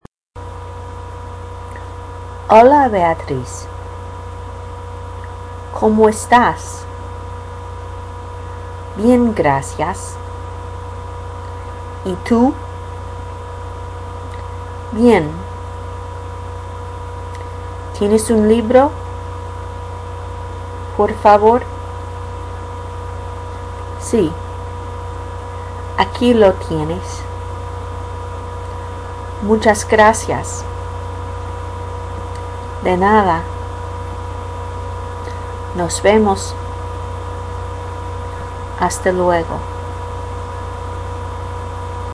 Dialogue 1b
Instructions: You will hear the dialogue broken up into parts for you to imitate.